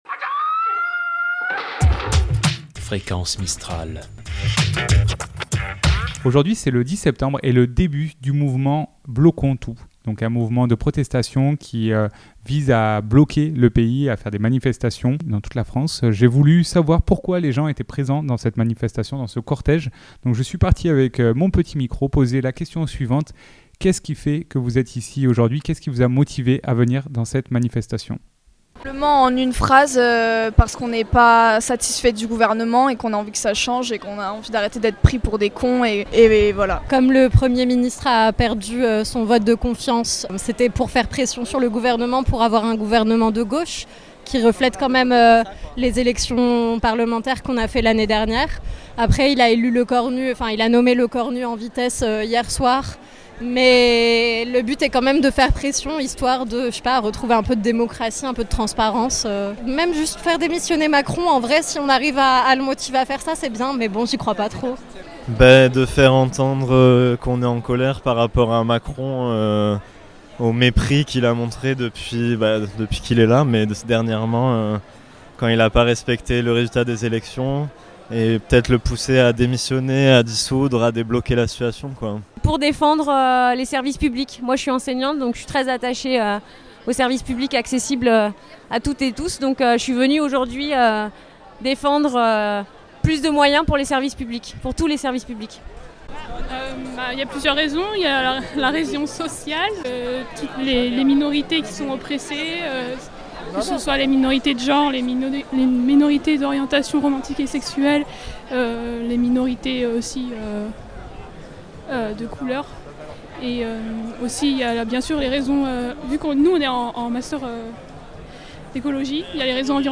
Micro Trottoir Manifestation - 10 sept Bloquons tout.mp3 (6.3 Mo)